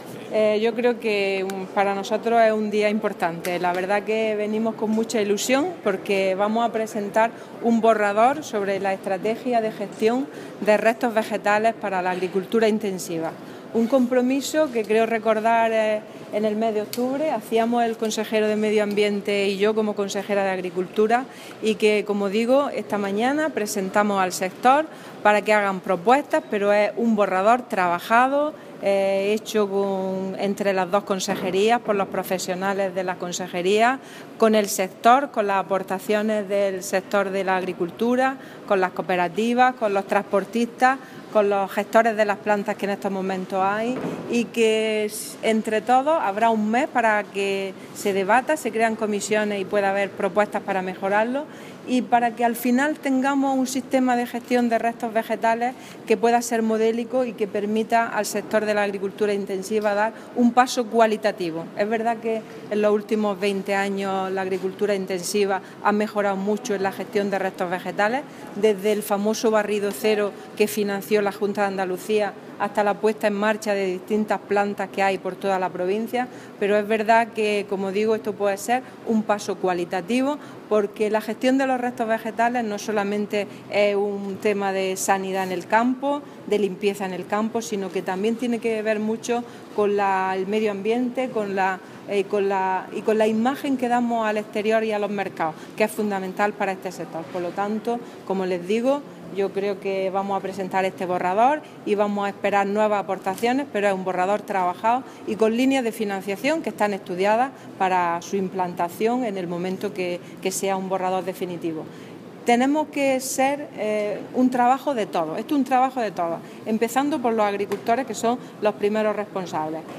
Declaracions de la consejera sobre la Estrategia de Gestión de Restos Vegetales en la Horticultura